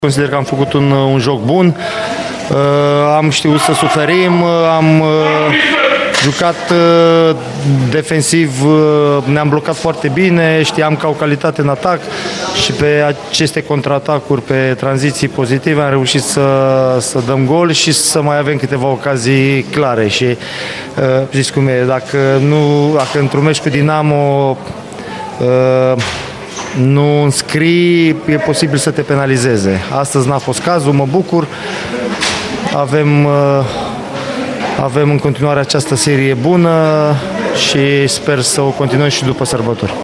Adrian Mihalcea a vorbit, la final, despre succesul în faţa „echipei sale de suflet”: